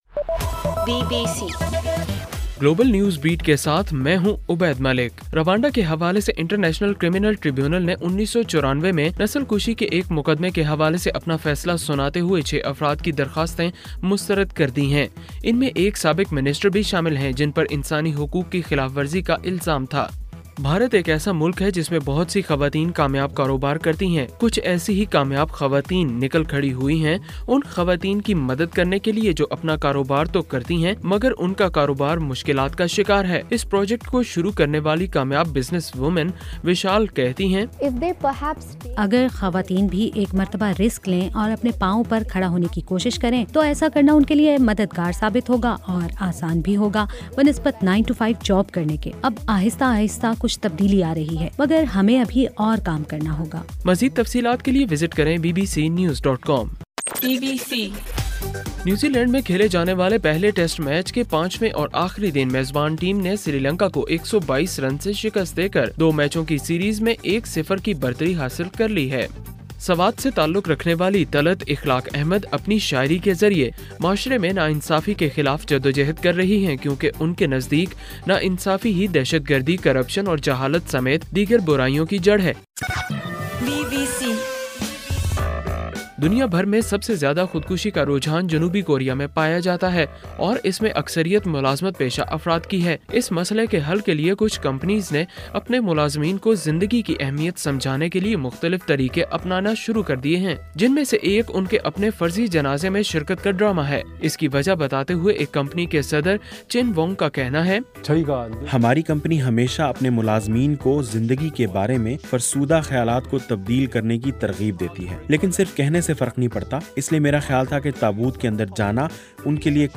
دسمبر 14: رات 8 بجے کا گلوبل نیوز بیٹ بُلیٹن